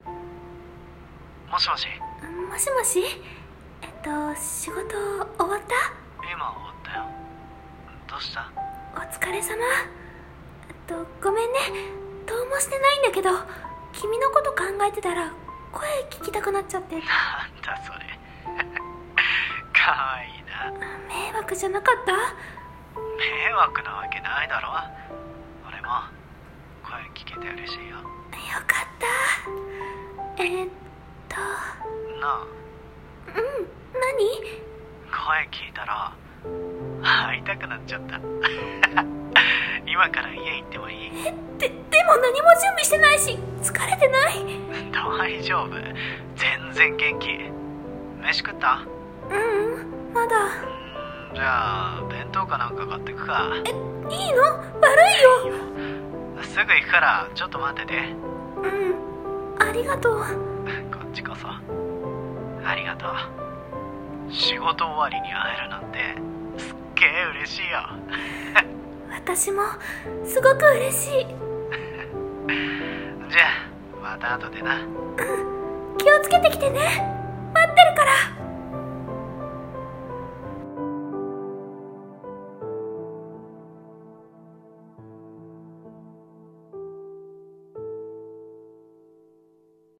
声劇:電話～声が聴きたくて～